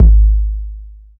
Long Room Reverb Kick Sound G# Key 260.wav
Royality free bass drum sound tuned to the G# note. Loudest frequency: 64Hz
long-room-reverb-kick-sound-g-sharp-key-260-d4w.mp3